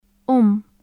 日本語の「マ・ミ・ム」と同じように，両唇を閉じたまま鼻から息を抜いて発音する/m/の音です。